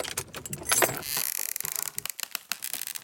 ChestOpen.ogg